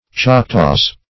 Meaning of choctaws. choctaws synonyms, pronunciation, spelling and more from Free Dictionary.
Search Result for " choctaws" : The Collaborative International Dictionary of English v.0.48: Choctaws \Choc"taws\, n. pl.; sing. Choctaw .